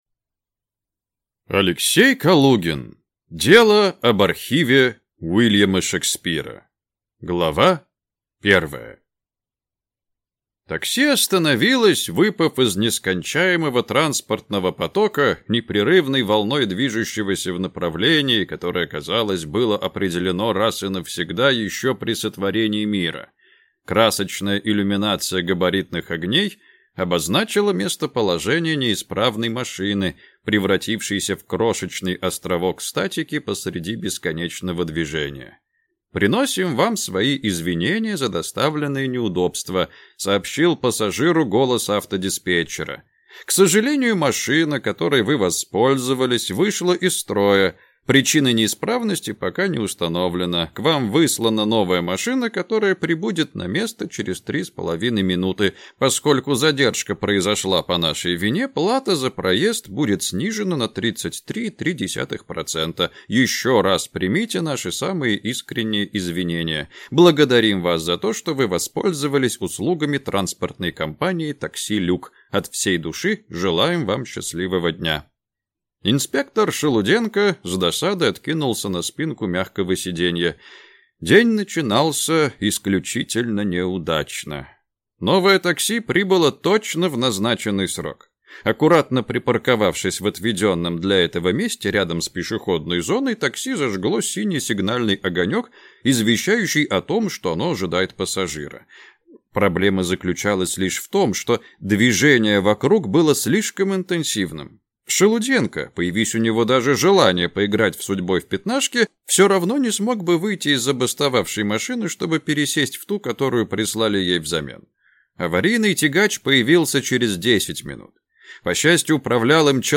Аудиокнига Дело об архиве Уильяма Шекспира | Библиотека аудиокниг